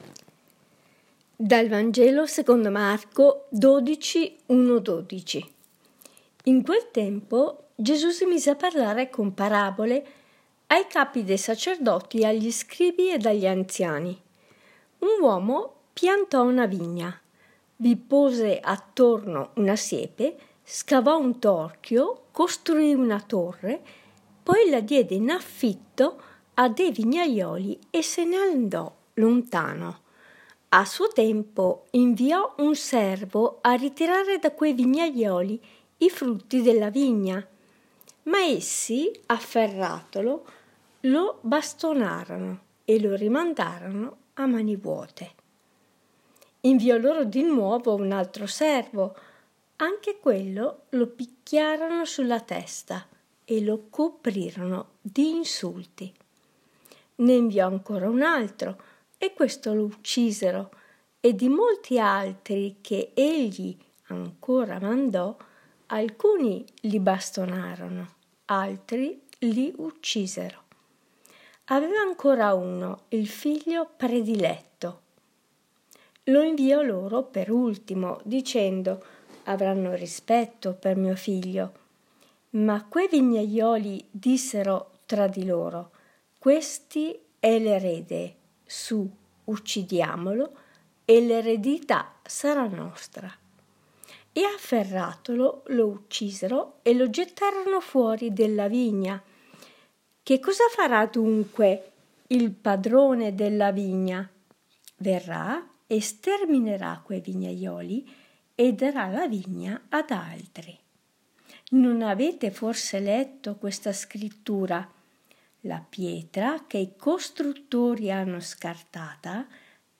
Commento al Vangelo